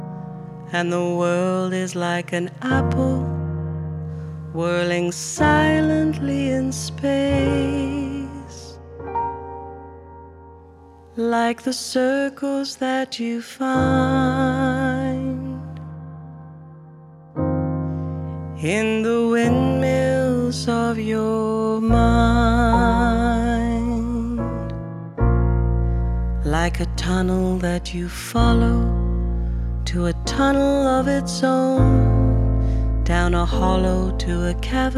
Жанр: Соундтрэки